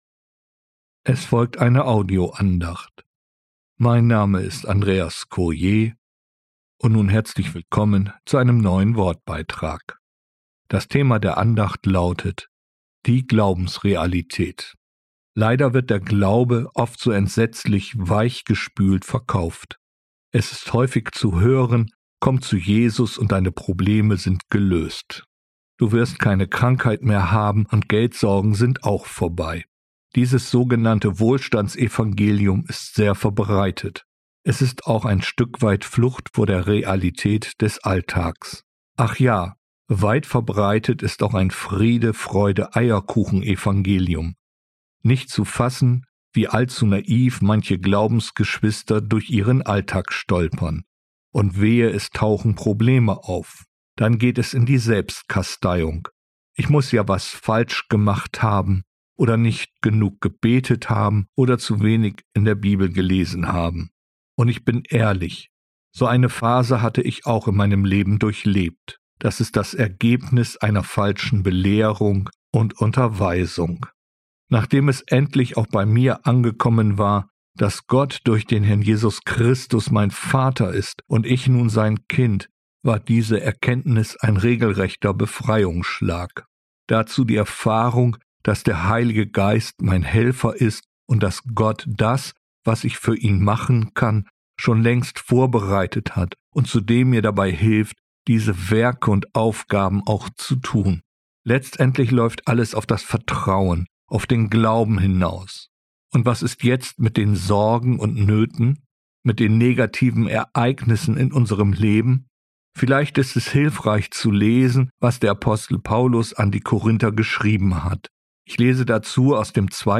Die Glaubensrealität, eine Audioandacht